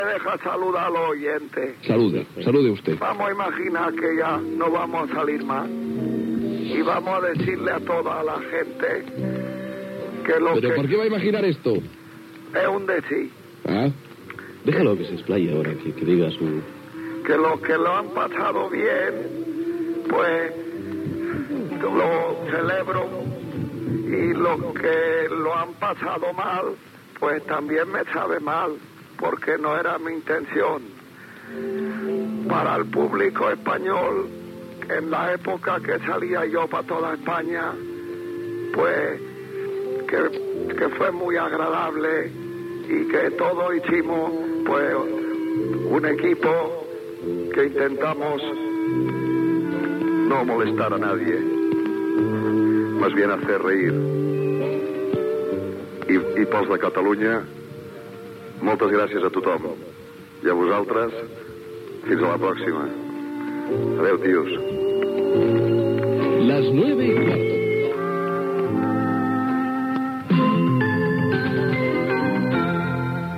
Hora i sintonia de la cadena.